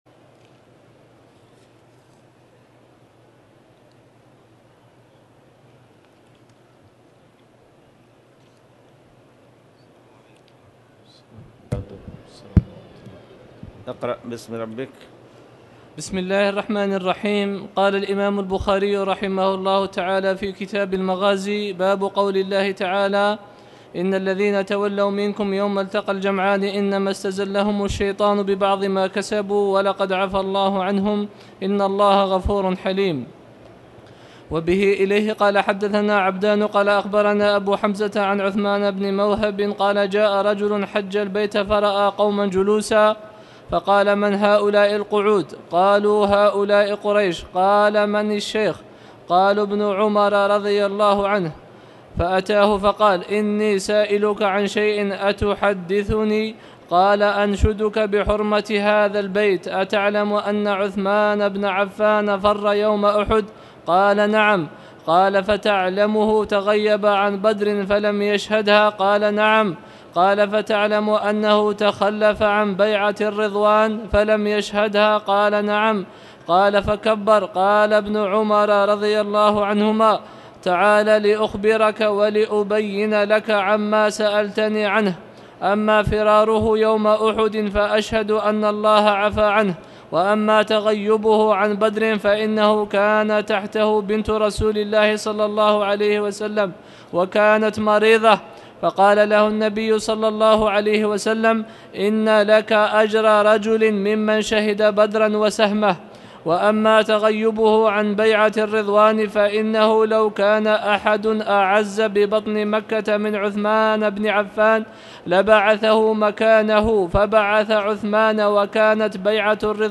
تاريخ النشر ١١ شوال ١٤٣٧ هـ المكان: المسجد الحرام الشيخ